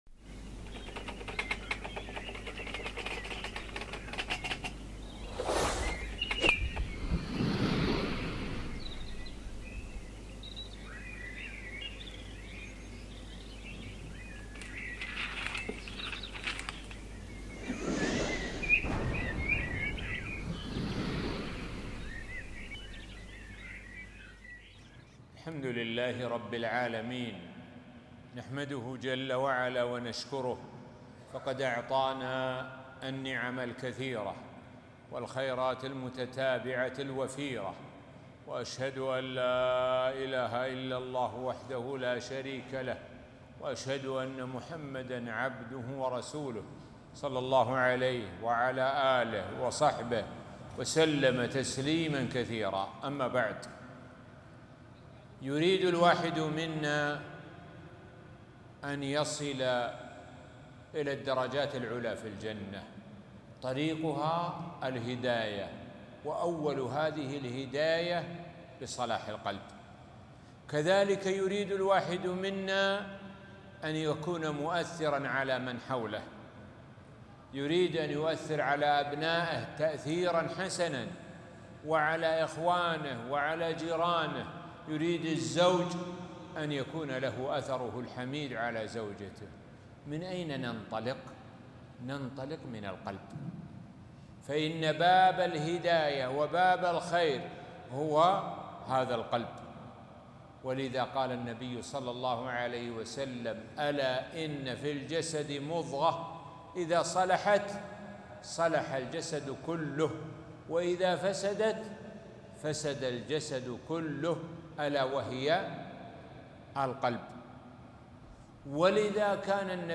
محاضرة قيمة - أعمـال القلـوب